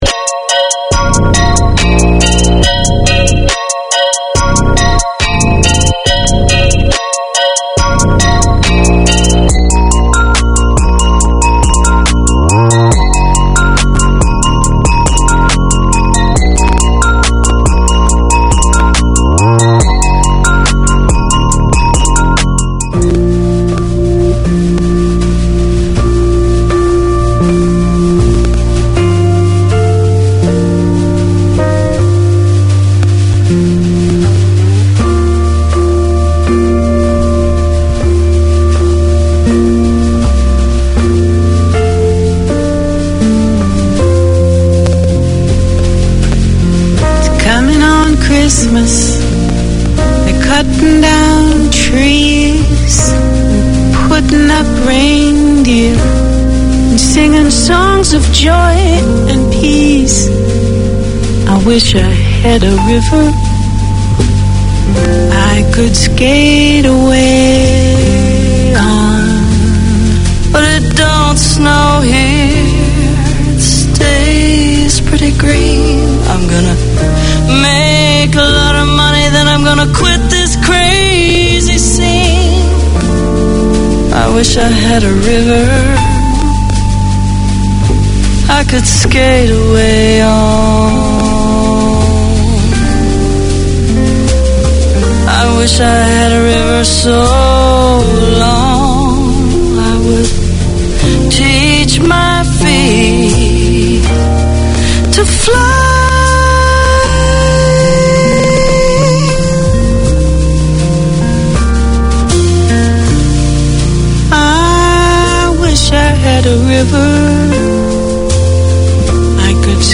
Catering to a wide community of Arabic, Syriac, Chaldean and Kurdish speakers, Voice of Mesopotamia presents an engaging and entertaining hour of radio. Tune in for interviews with both local guests and speakers abroad, a youth-led segment and music from across Mesopotamia.